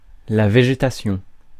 Ääntäminen
Synonyymit flore végétalité Ääntäminen France: IPA: [ve.ʒe.ta.sjɔ̃] Haettu sana löytyi näillä lähdekielillä: ranska Käännös Substantiivit 1. вегетация Muut/tuntemattomat 2. растителност {f} (rastítelnost) Suku: f .